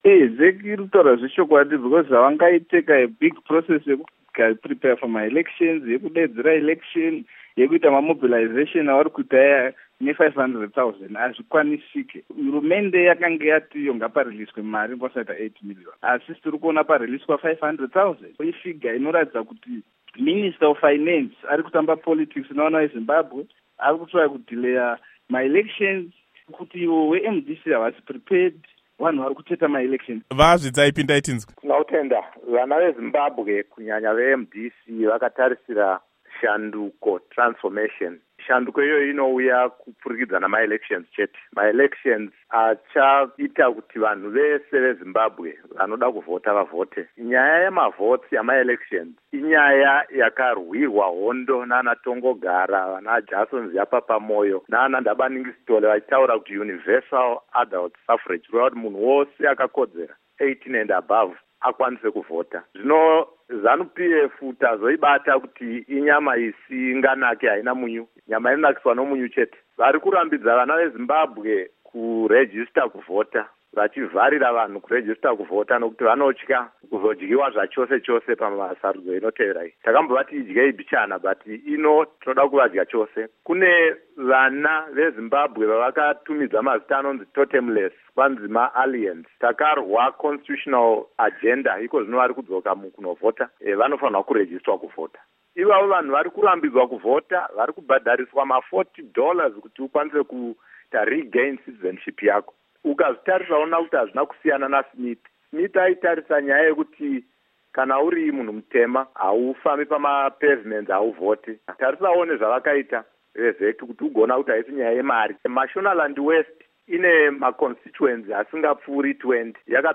Hurukuro naVaCecil Zvidzai naVaPsychology Mazivisa